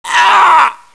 mil_pain4.wav